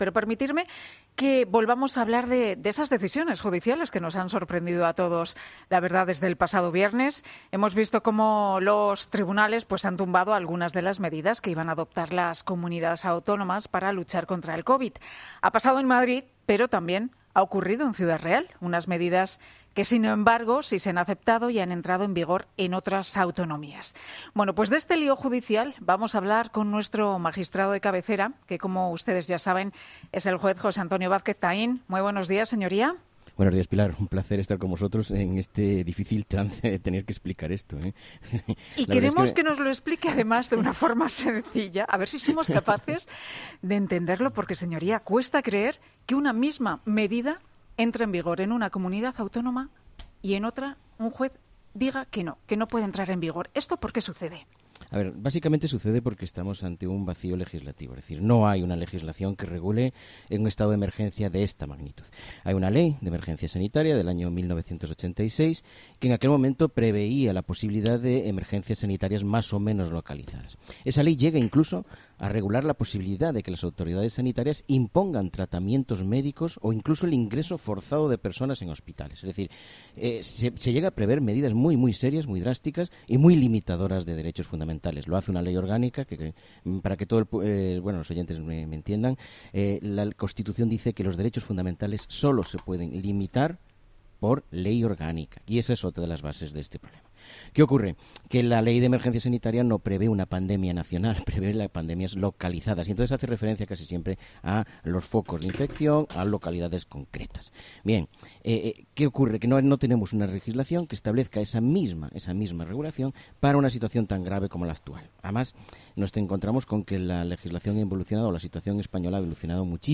El juez José Antonio Vázquez Taín analiza en "Herrera en COPE' esta decisión.